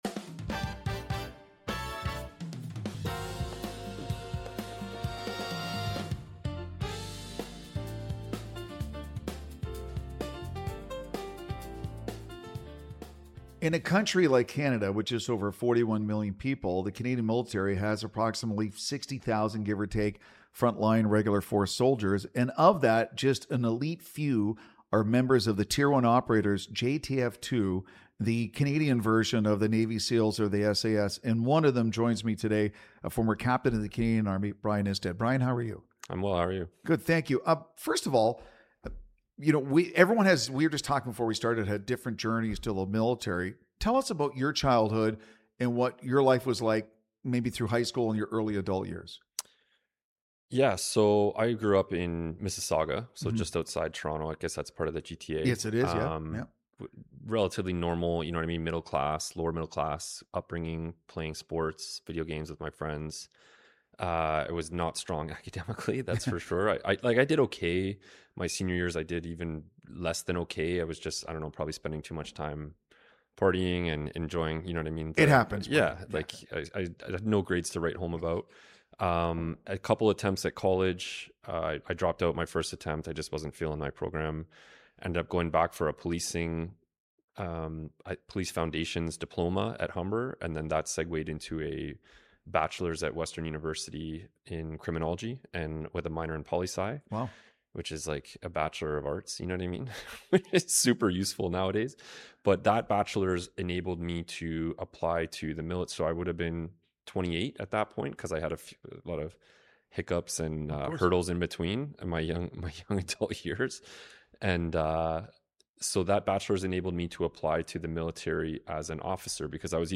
… continue reading 15 episodes # Daily News # News Talk # News # True Patriot Love